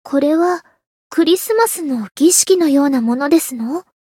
灵魂潮汐-安德莉亚-圣诞节（摸头语音）.ogg